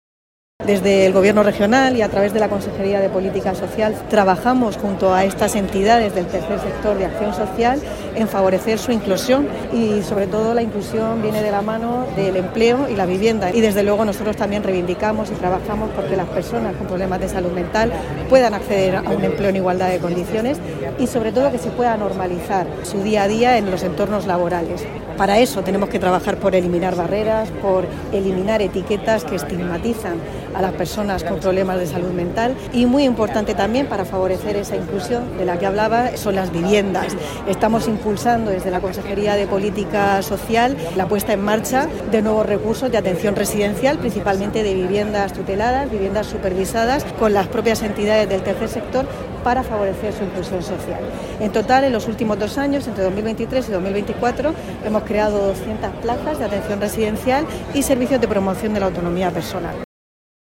Sonido/ Declaración del consejero de Salud, Juan José Pedreño [mp3], en el acto de conmemoración por el Día Mundial de la Salud Mental.